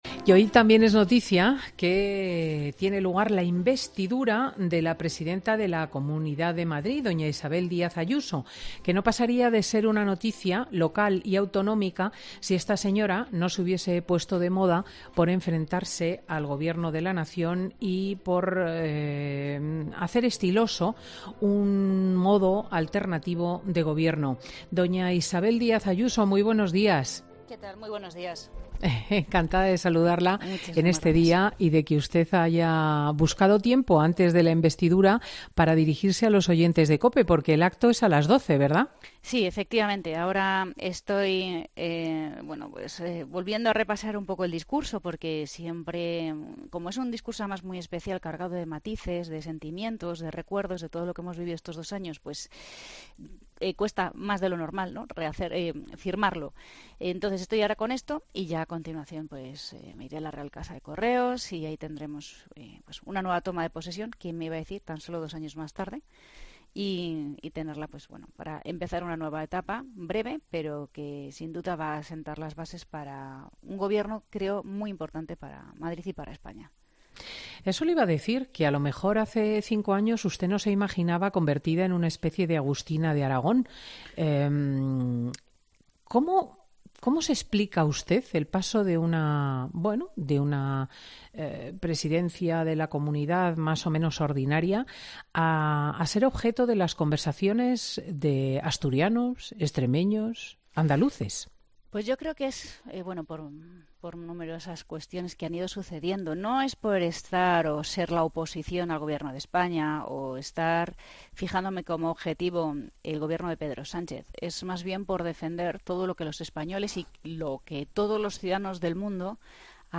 Isabel Díaz Ayuso es oficialmente desde este sábado investida por segunda vez en dos años como presidenta de la Comunidad de Madrid y, desde los micrófonos de Fin de Semana de COPE, ha dejado un recado al presidente de la CEOE , Antonio Garamendi , por sus palabras sobre los indultos a los presos del procés.